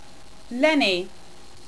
Pronunciation: Lenny - len-ee , Lennies - len-ees